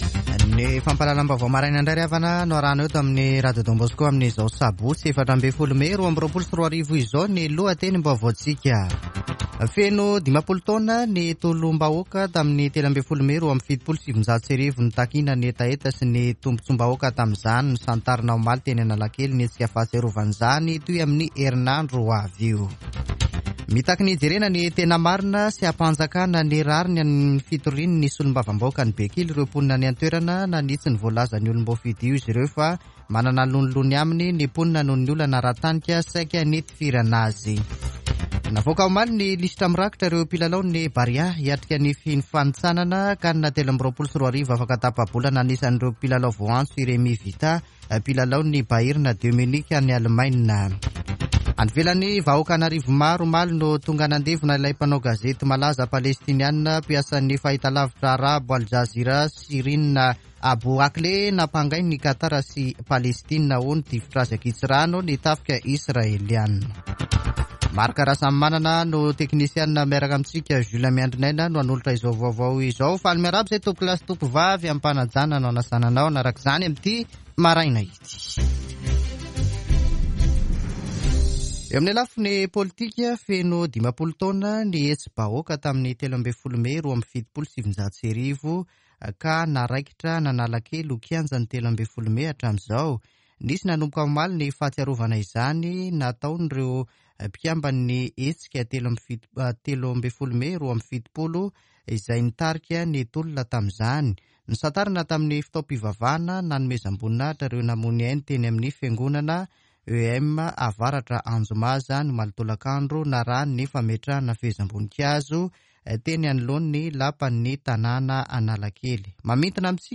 [Vaovao maraina] Sabotsy 14 mey 2022